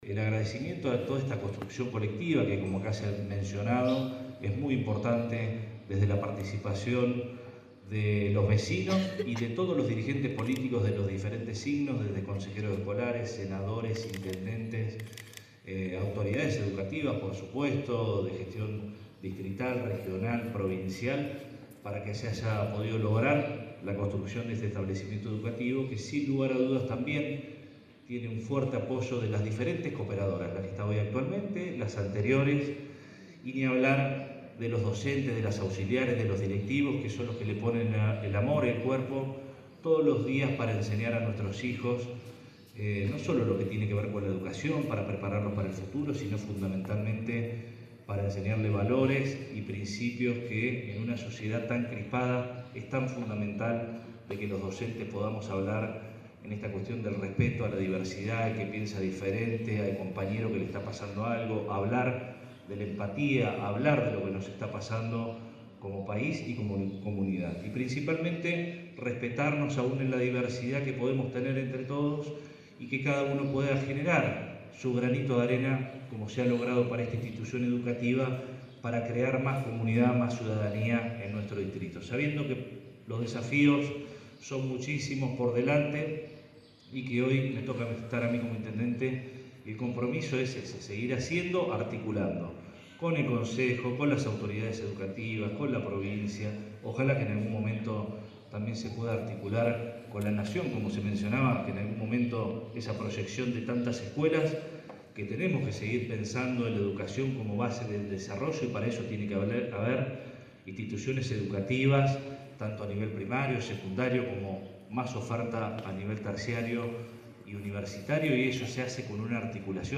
El acto contó con la participación de toda la comunidad educativa, autoridades municipales y provinciales, y fue encabezado por el intendente Arturo Rojas, quien brindó un mensaje de reconocimiento y afecto al establecimiento.